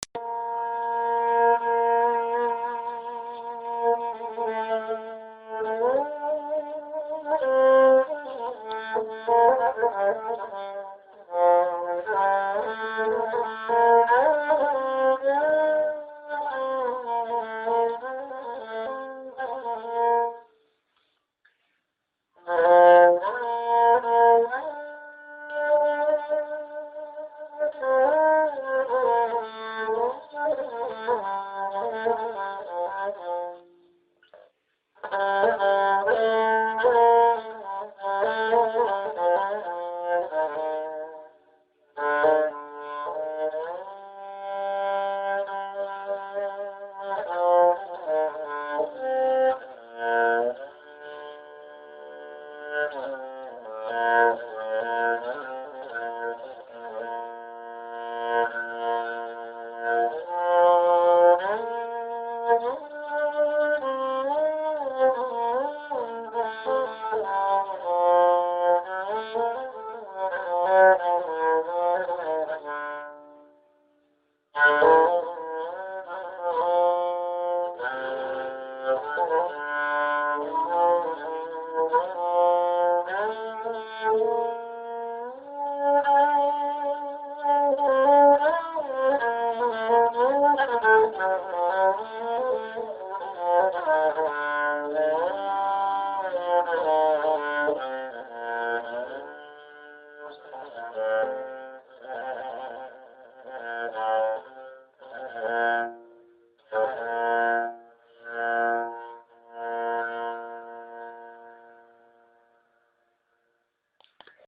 REBAB (Rebap, Rubap, Rubab, Robab)